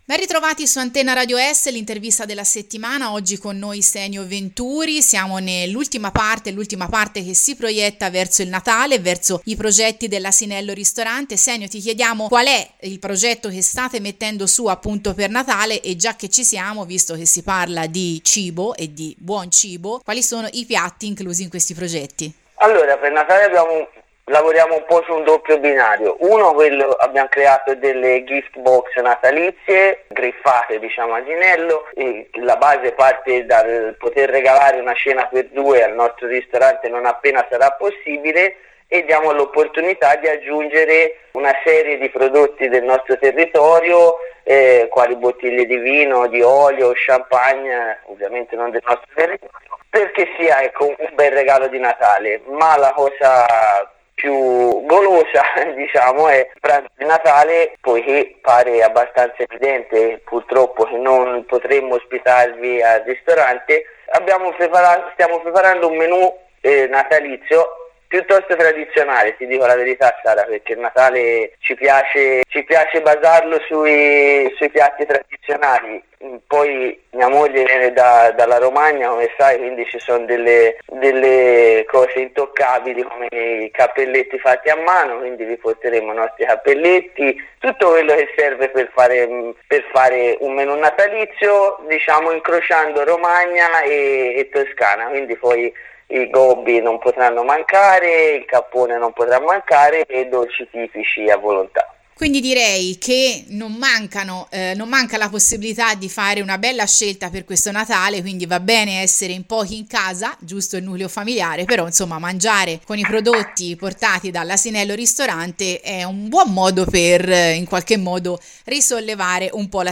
Sotto l’intervista completa.